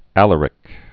(ălər-ĭk) AD 370?-410.